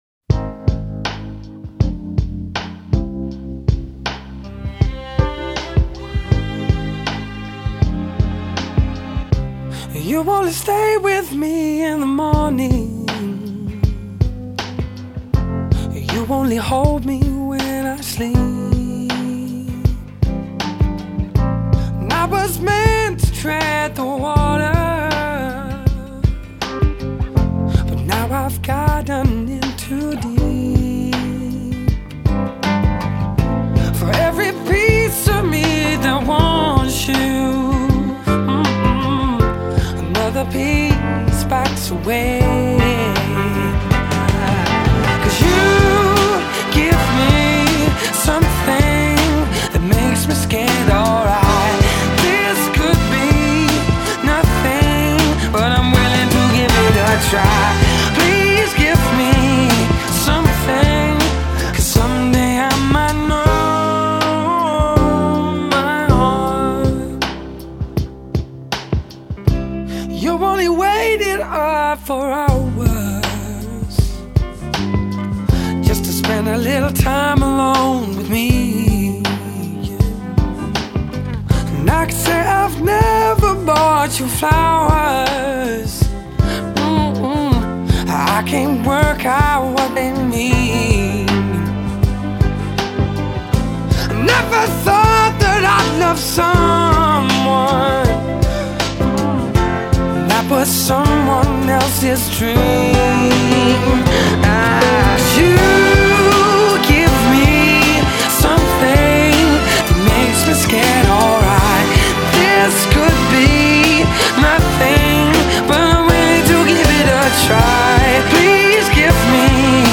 Genre: Pop/Soul/Rock
平滑中带有一丝嘶哑，天生一副唱Soul的嗓子。
但是歌曲中又能听出几分Rock元素。